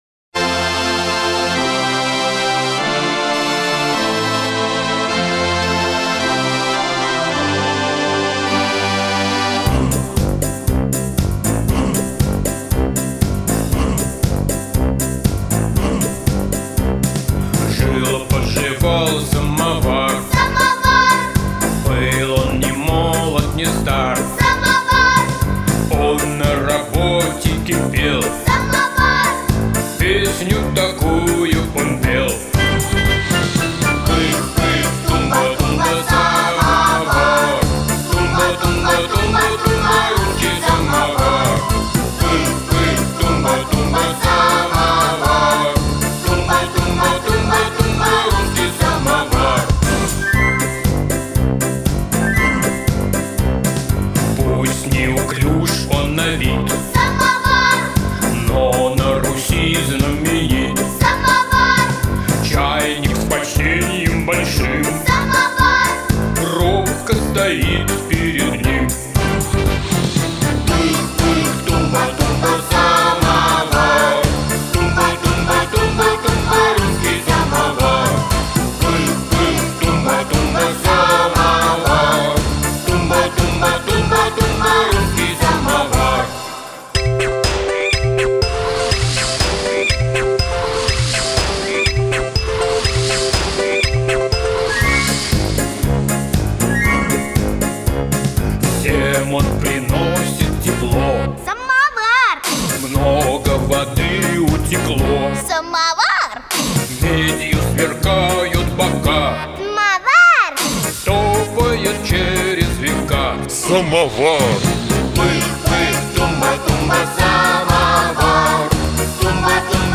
детской песенке